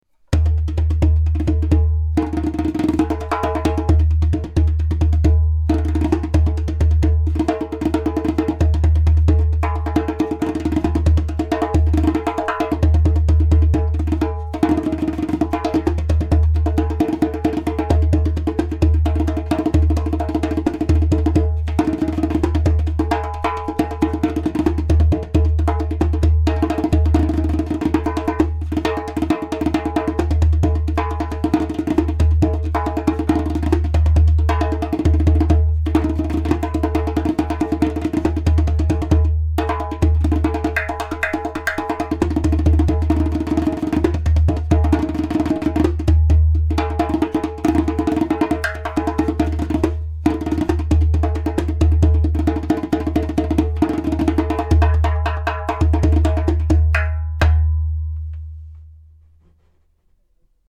• Strong and super easy to produce clay kik (click) sound
• Beautiful harmonic overtones.
• Medium thickness goat skin (~0.3mm)
• Body: Ceramic / Clay